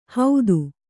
♪ haudi